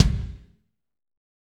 Index of /90_sSampleCDs/Northstar - Drumscapes Roland/KIK_Kicks/KIK_F_R Kicks x